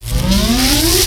I wanted a more detailed sound, so i tried combining it with RA2's, and with some adjusting, it turned out pretty nice.
combined RA1 & RA2 Tesla coil sounds for presence & intimidation